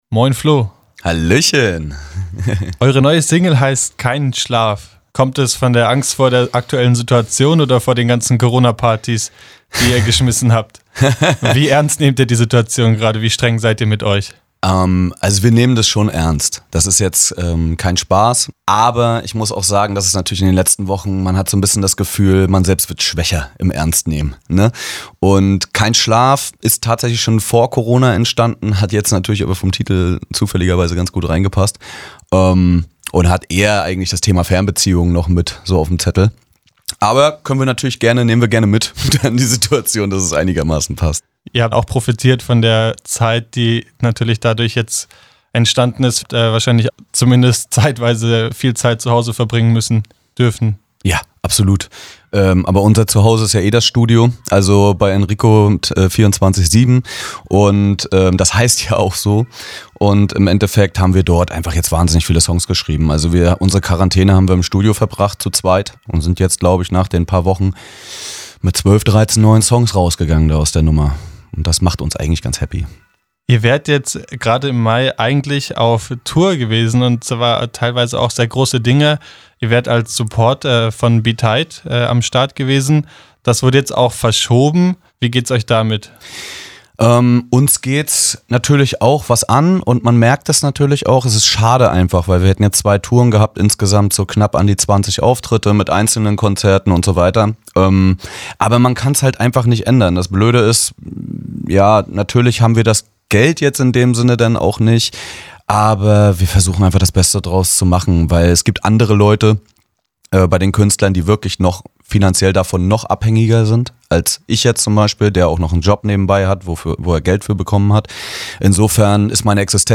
Spontan gab es ein kleines Interview.